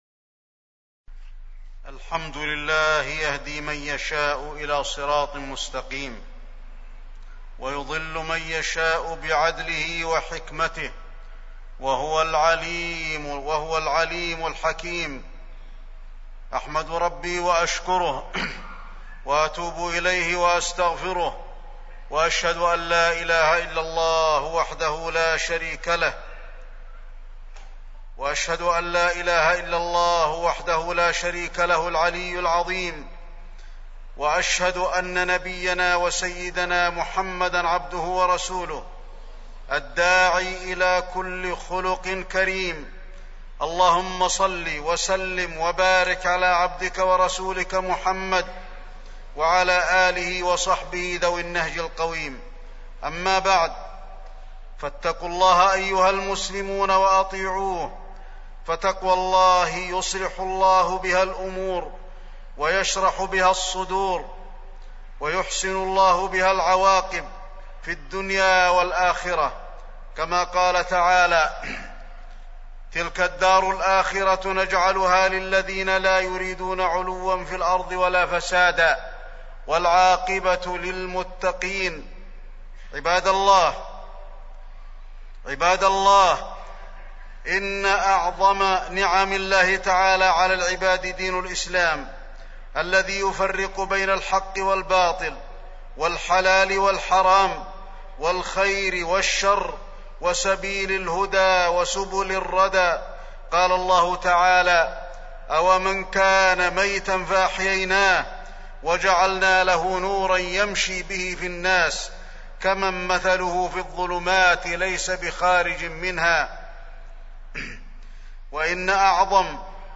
تاريخ النشر ٥ شعبان ١٤٢٦ هـ المكان: المسجد النبوي الشيخ: فضيلة الشيخ د. علي بن عبدالرحمن الحذيفي فضيلة الشيخ د. علي بن عبدالرحمن الحذيفي البدع المضلة